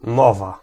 Ääntäminen
US : IPA : [ˈspiːt͡ʃ]